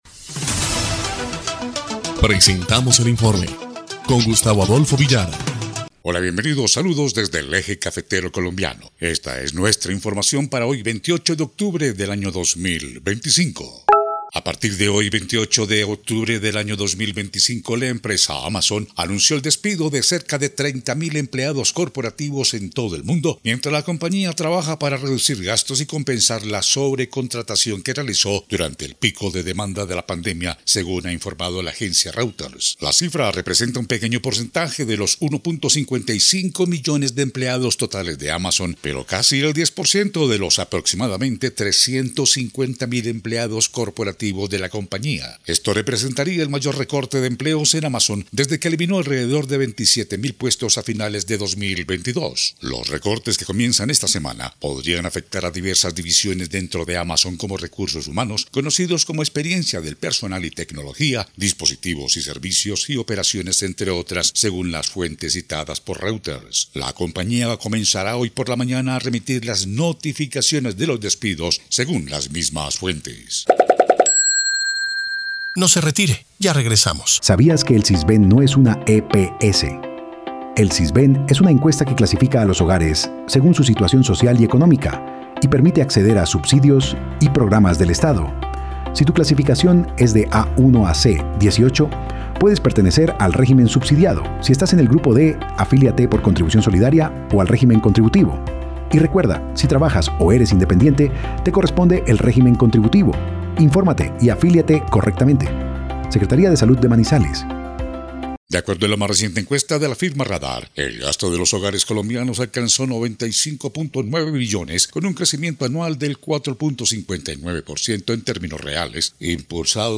EL INFORME 1° Clip de Noticias del 28 de octubre de 2025